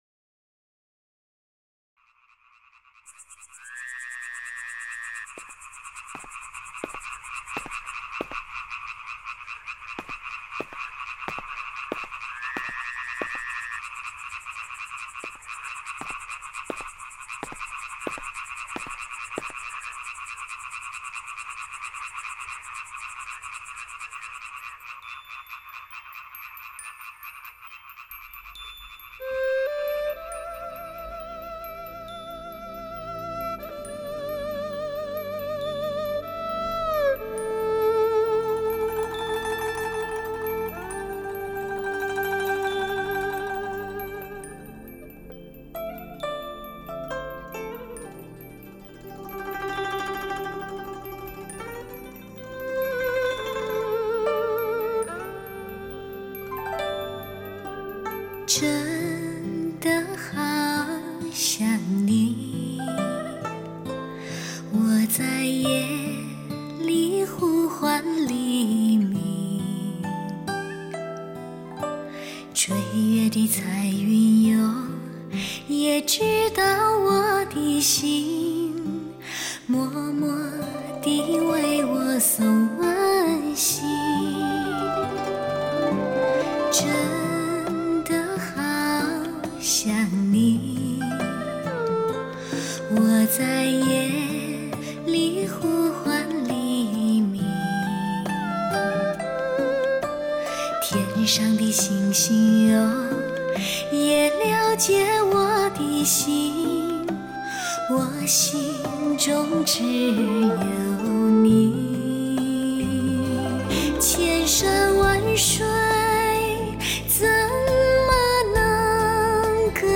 略带沙哑的性感嗓音恣情
挥晒，歌声与器乐于巧夺天工的辉映中激荡出惊艳四座的动人乐音。